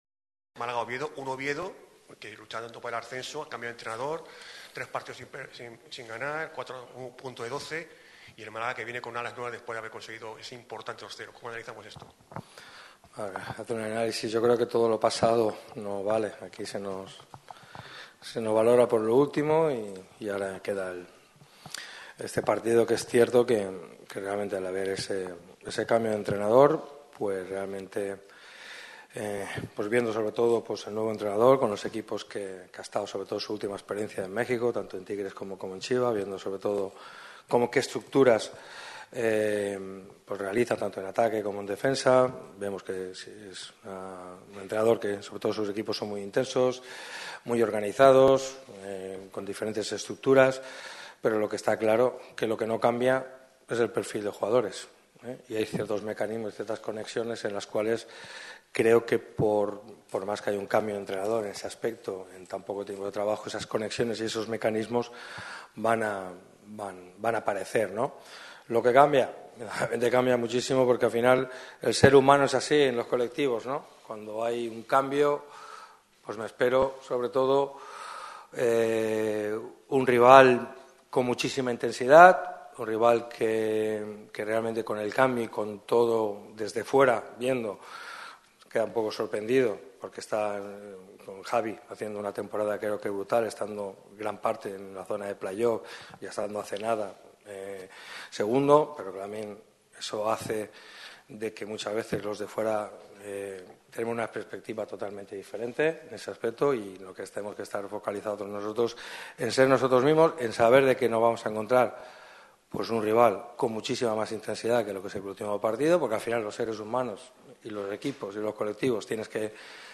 El entrenador del Málaga CF ha comparecido ante los medios de comunicación en la previa del duelo que enfrentará a los boquerones contra el Real Oviedo en el partido perteneciente a la jornada 33 de LaLiga Hypermotion.